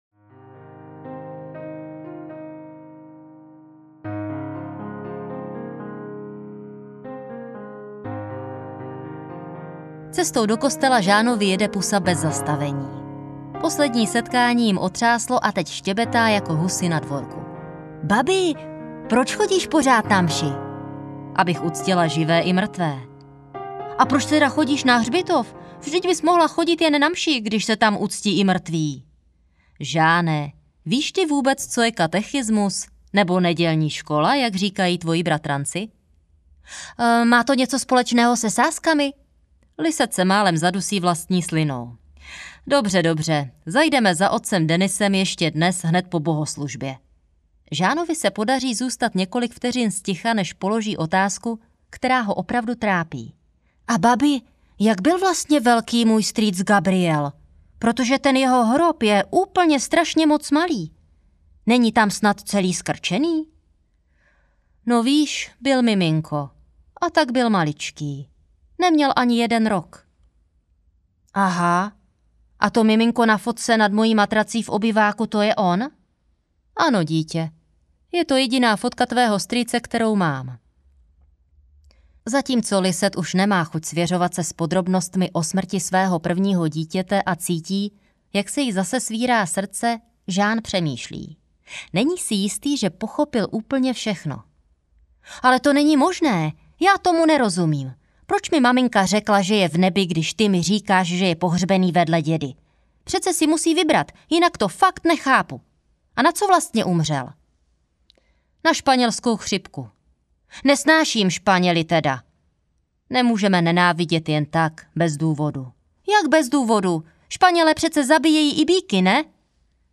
Když má štěstí kliku audiokniha
Ukázka z knihy
kdyz-ma-stesti-kliku-audiokniha